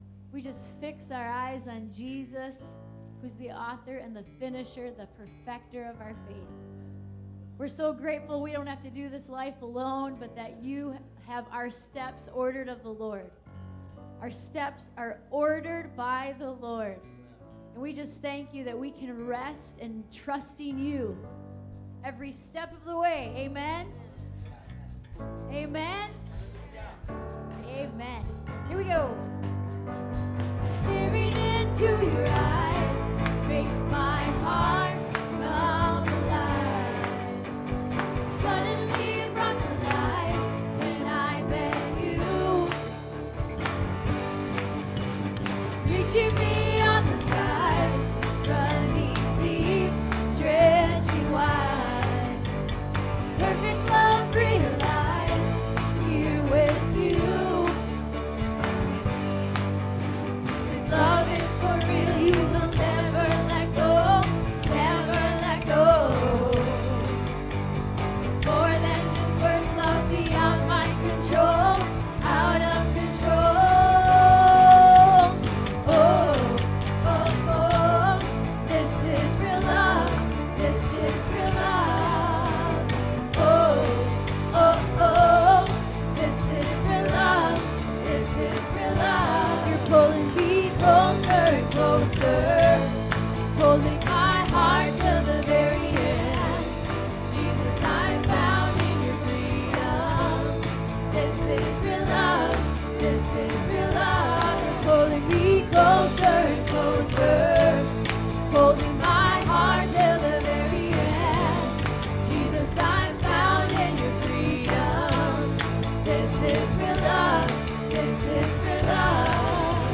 church service